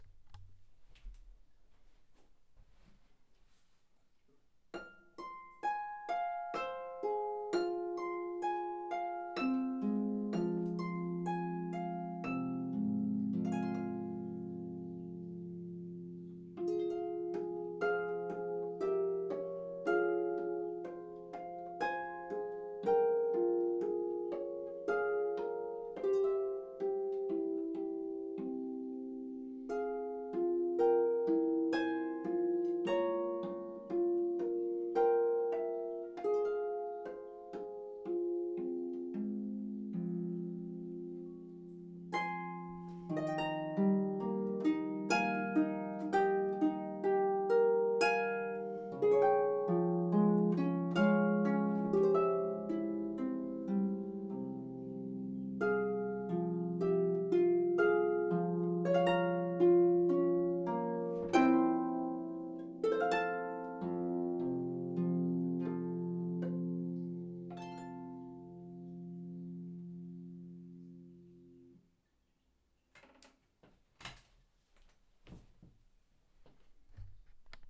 traditional English carol
solo pedal harp